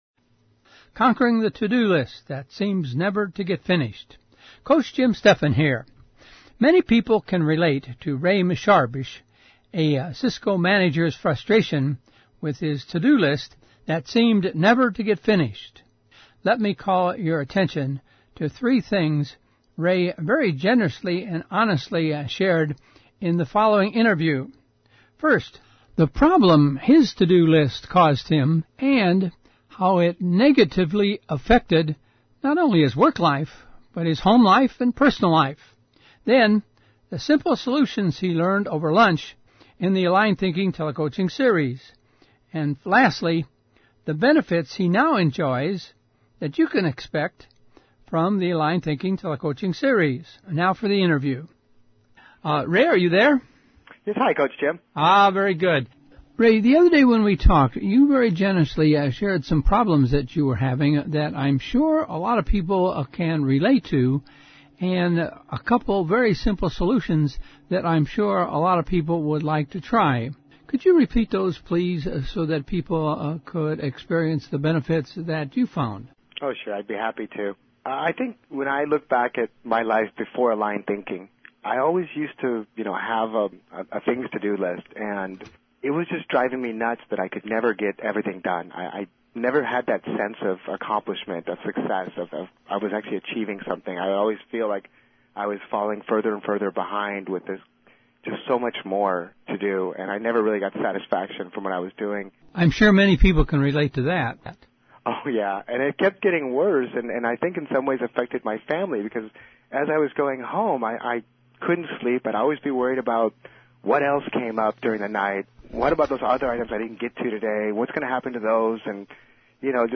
Conquering The To-Do List— An Interview